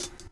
Wu-RZA-Hat 23.WAV